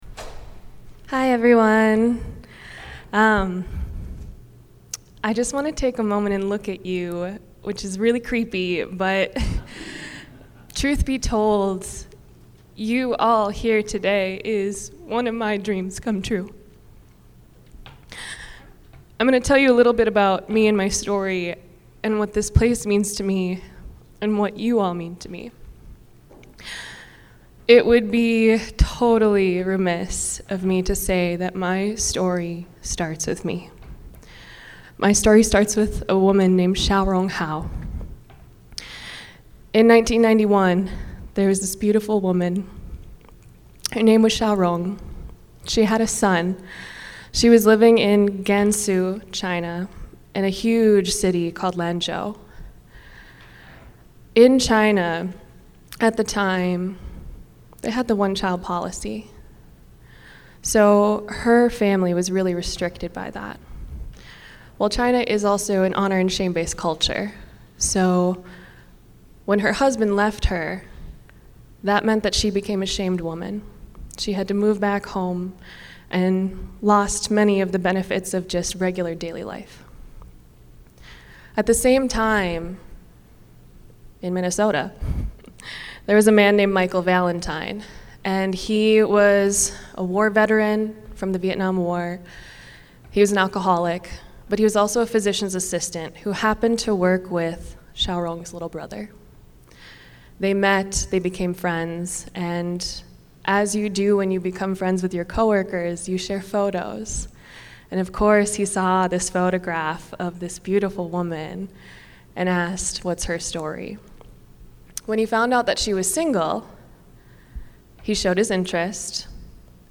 Testimonials